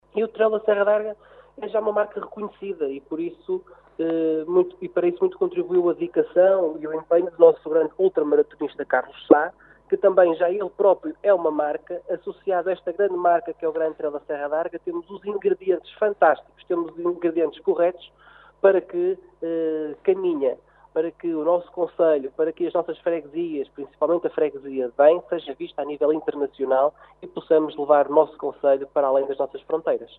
O vereador Rui Lages não tem dúvida da importância que o GTSA tem para a projeção do concelho de Caminha além fronteiras e sublinha  o papel predominante do ultramaratonista Carlos Sá e da sua empresa na consolidação deste evento desportivo.